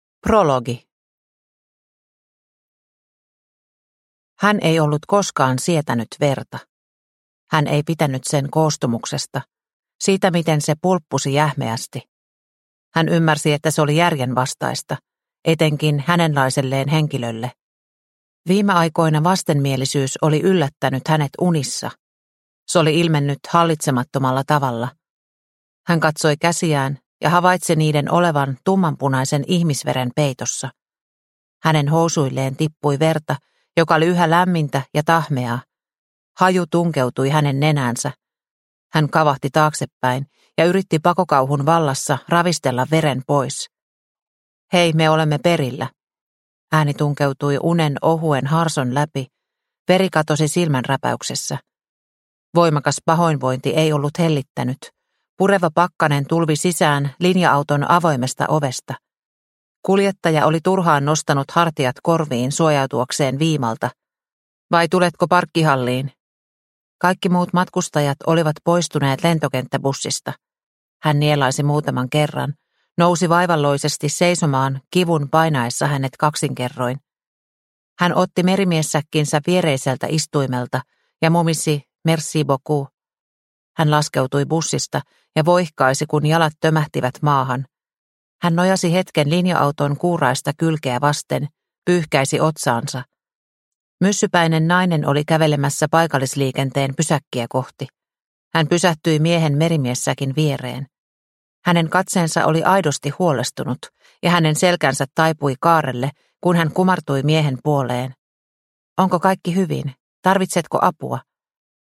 Punainen susi – Ljudbok – Laddas ner